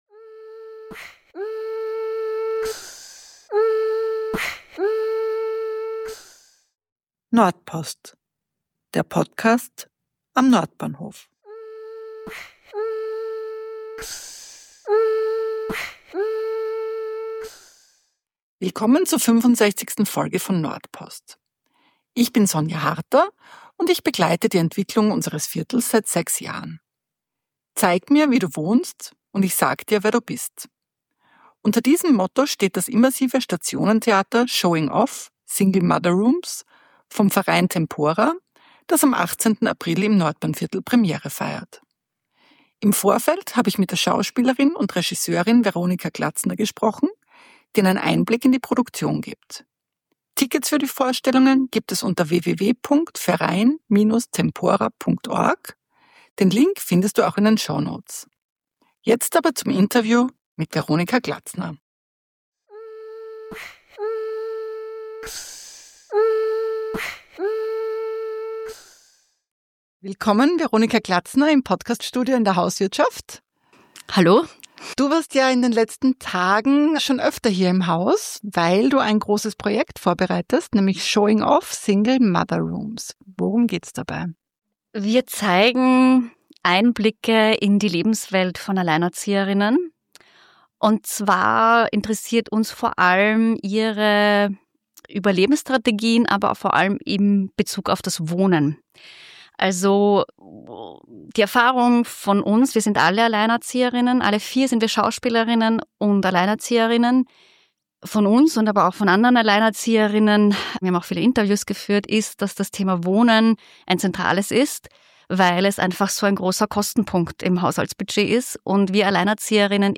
Gespräch über die geplante Straßenbahnlinie